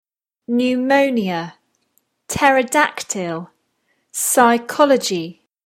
4. The unspoken P in pneumonia, pterodactyl, and psychology
pneumonia-pterodactyl-psychology.mp3